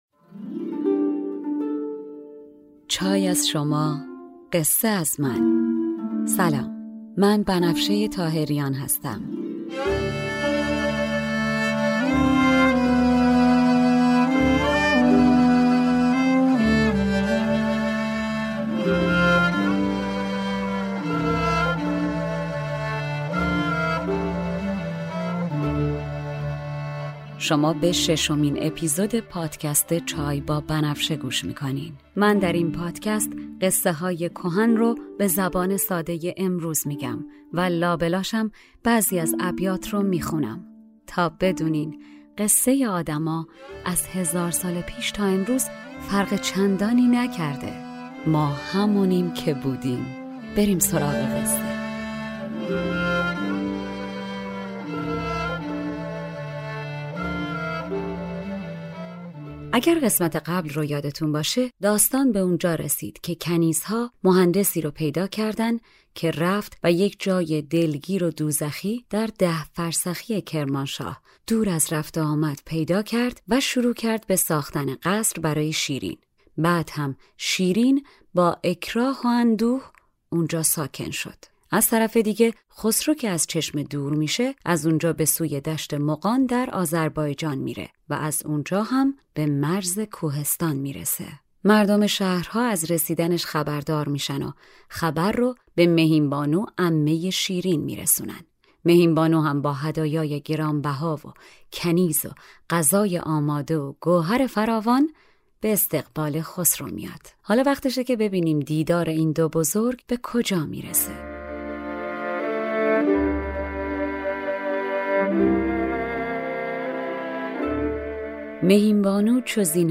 ما در این پادکست قصه های کهن را به زبان امروزی بازگو می کنیم و در میان آنها ابیاتی را هم می خوانیم